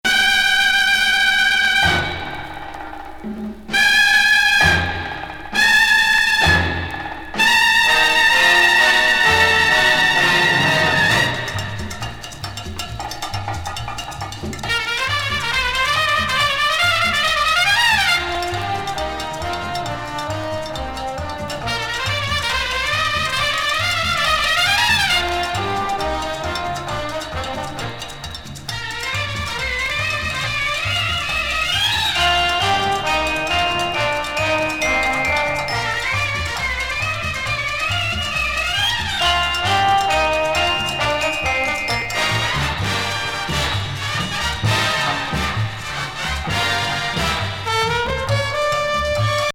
アルバムのように細部まで作りこまれたラテン・ブラス作!管楽器パートが華やかな